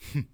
xys嘲弄2.wav 0:00.00 0:00.35 xys嘲弄2.wav WAV · 30 KB · 單聲道 (1ch) 下载文件 本站所有音效均采用 CC0 授权 ，可免费用于商业与个人项目，无需署名。